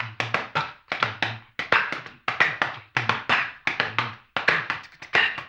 HAMBONE 06-R.wav